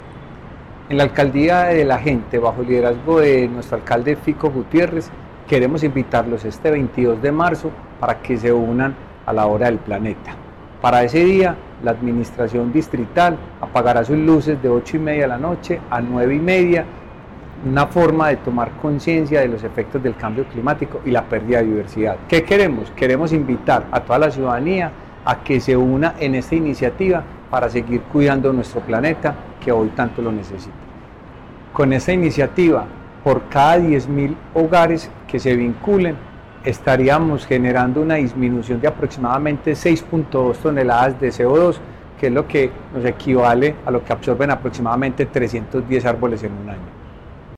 Palabras de Carlos Velásquez, subsecretario de Gestión Ambiental